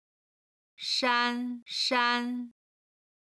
杉山(shān shān)